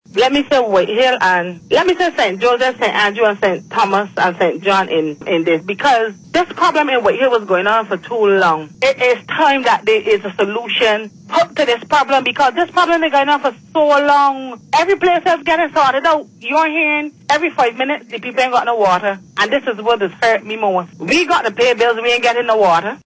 The Deputy Prime Minister gave the assurance as she fielded questions from members of the public, including this long-time resident of White Hill, St. Andrew.